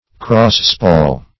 What does cross-spall mean? Meaning of cross-spall. cross-spall synonyms, pronunciation, spelling and more from Free Dictionary.